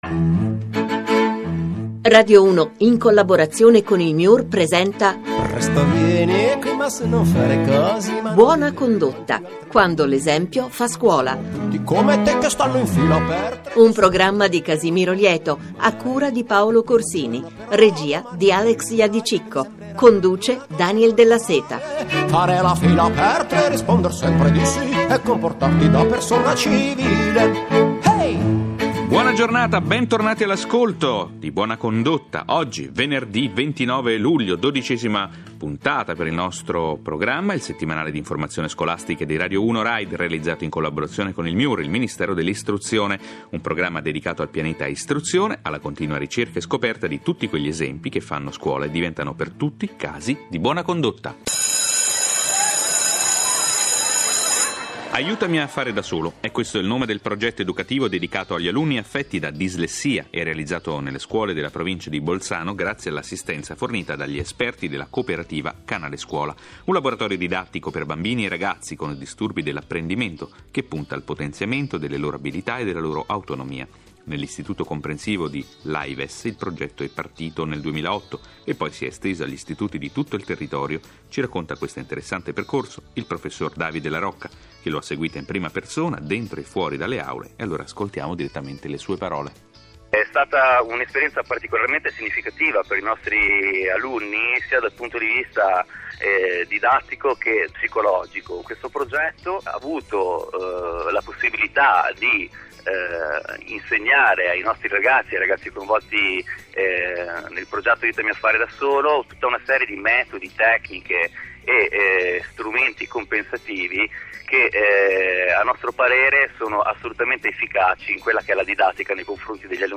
29 luglio 2011 RADIO 1 ha dedicato uno spazio alla presentazione del progetto.